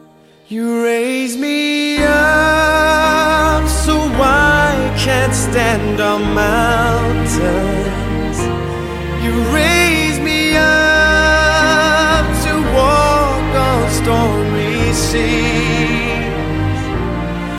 01 - 元音连读 (w/j)
连读时候，在两个元音之间加入/w/音或者/j/音，作为平滑过度。